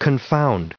163_confound.ogg